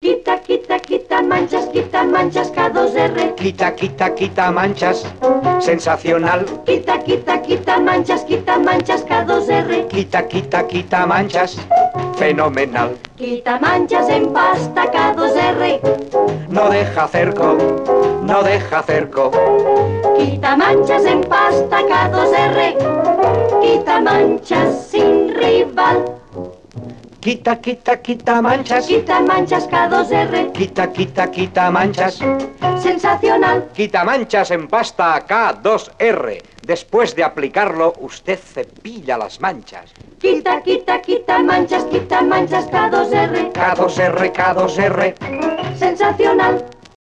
Publicitat cantada